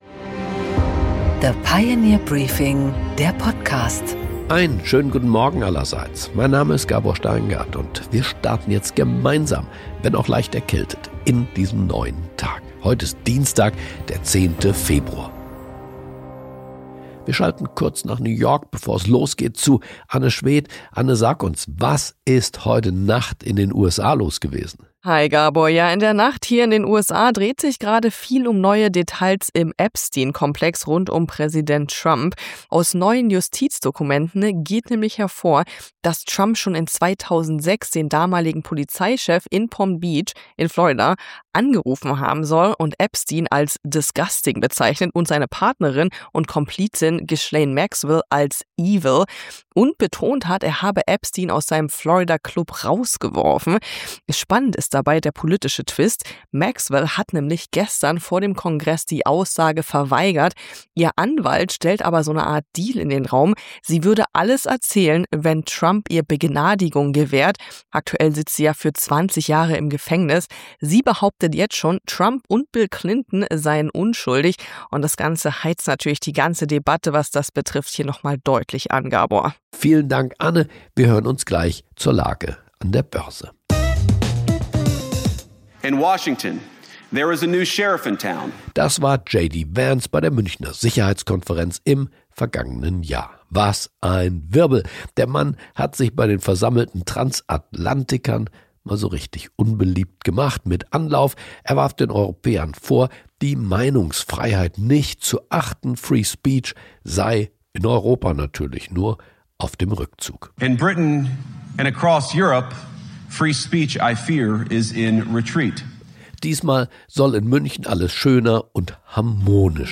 Gabor Steingart präsentiert das Pioneer Briefing.
Im Gespräch: Christian Lindner, Ex-Finanzminister und heute Vorstand der Autoland AG, spricht mit Gabor Steingart über seinen Neustart in dem Mittelstandsunternehmen, die Lage der deutschen Autoindustrie im Wettbewerb mit China und seine Sicht auf die schwarz-rote Bundesregierung unter Merz.